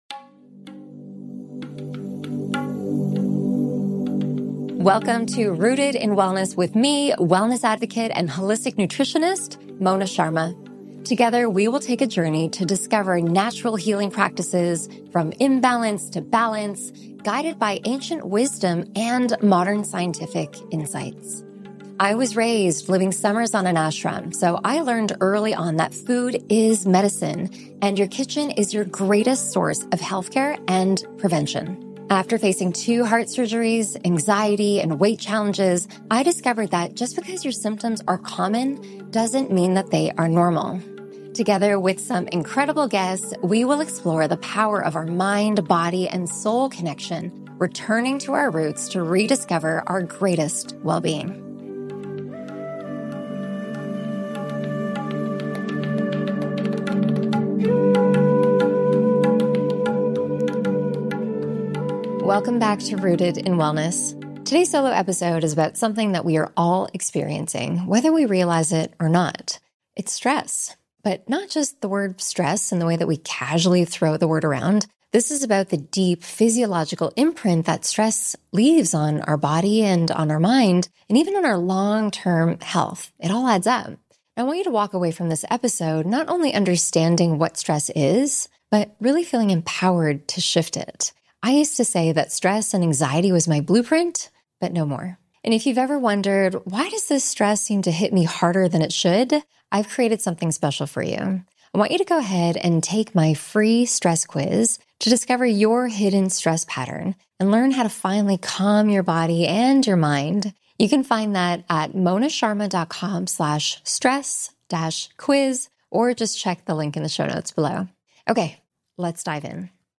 Today’s solo episode is about something we’re all experiencing, whether we realize it or not: stress. But not just stress in the casual sense, the deep, physiological stress that imprints on your body, your mind, and your long-term health.